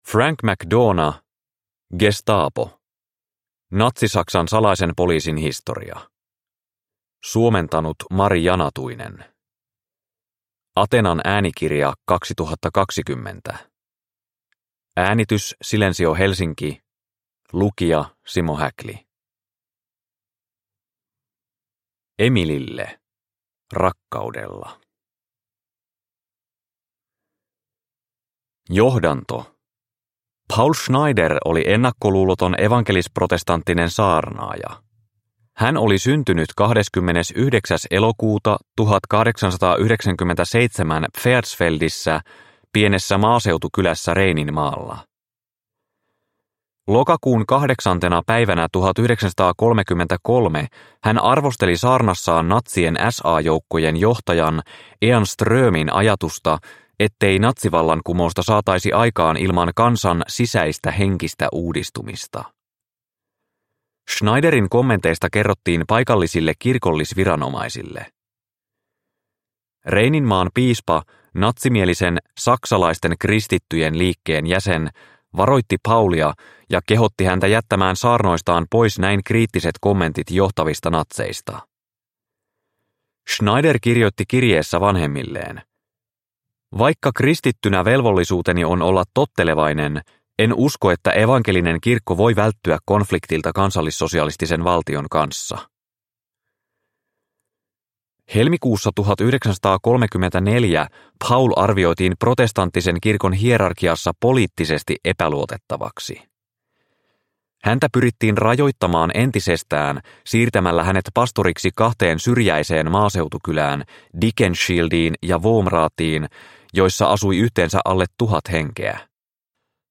Gestapo – Ljudbok – Laddas ner